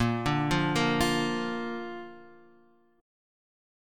A# Chord